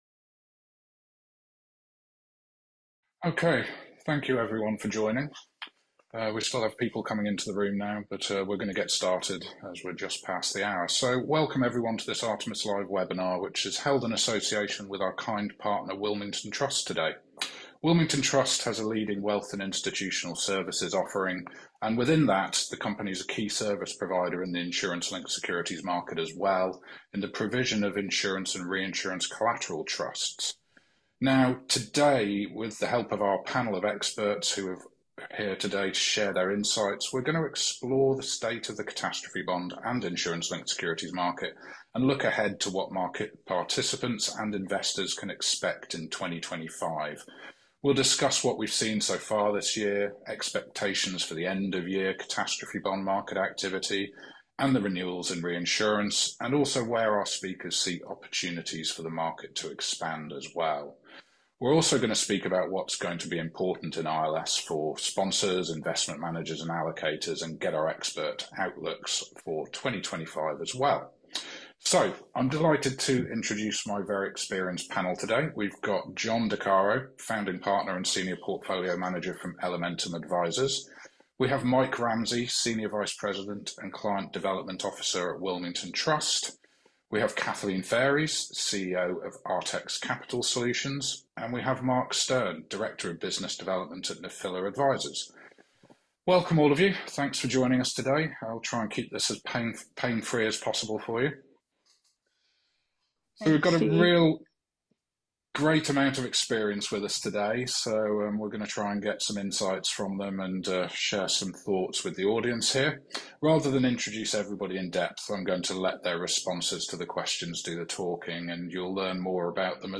This is a recording of our ILS Market Outlook 2025 live webinar that was held on October 31st 2024 and featured insurance-linked security (ILS) industry experts that joined us to discuss the state of the catastrophe bond and ILS market and provide their o